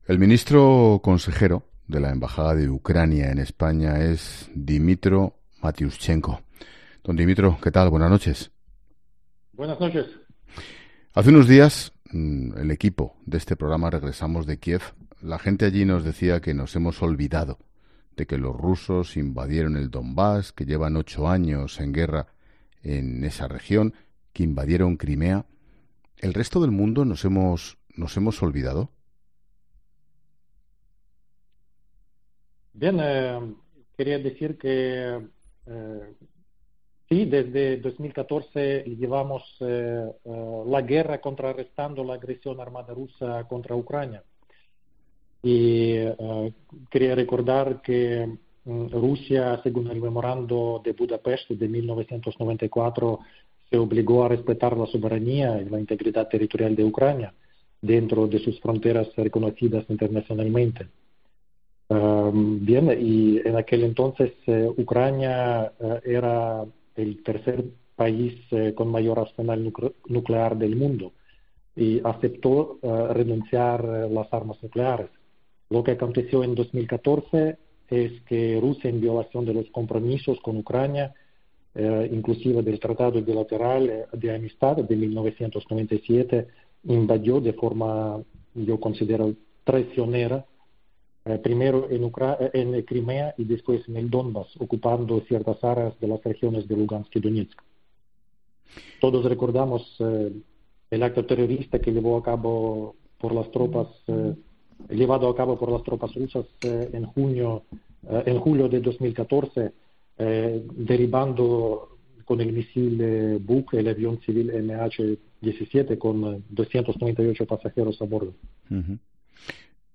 Hoy, en La Linterna de COPE, Ángel Expósito ha charlado con el Ministro Consejero de Ucrania en España es Dmytro Matiuschenko, sobre la situación que actualmente atraviesa su país ante la amenaza militar de Rusia, desplegada a lo largo de toda la frontera.